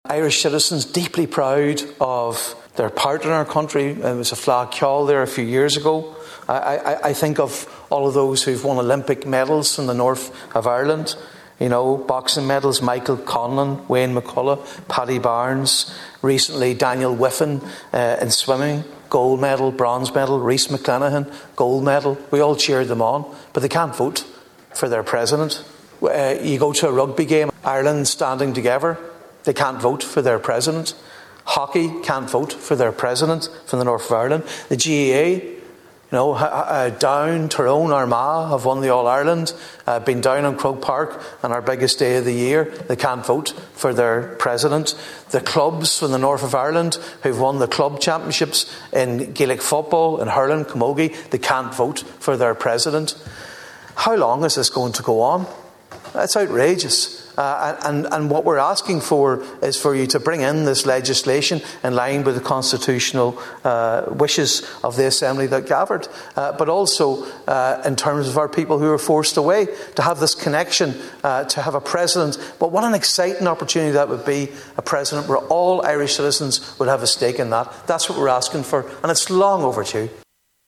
A Donegal TD has supported calls which would allow people in Northern Ireland to vote in Irish Presidential elections. Sinn Féin Deputy, Padraig Mac Lochlainn, speaking in the Dáil yesterday evening, said people in the North have been contributing to achievements in Irish sport for years, and yet cannot vote for President.